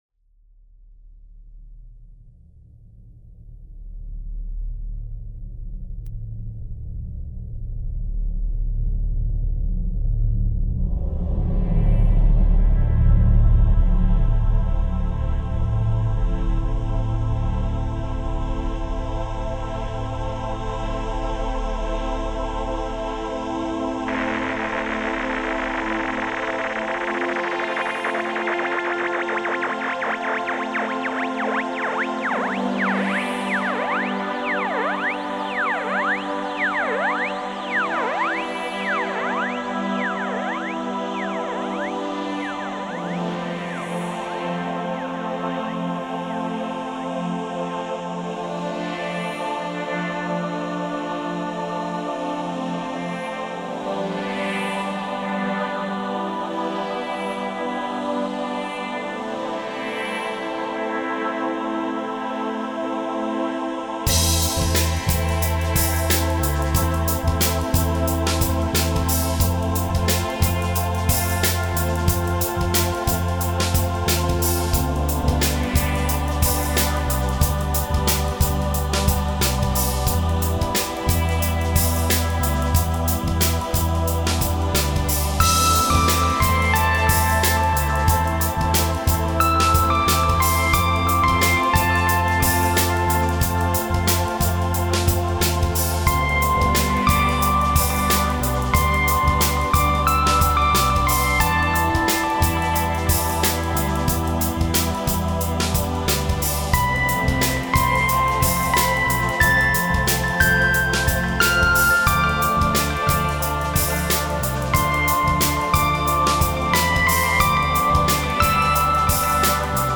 Electronic music inspired by space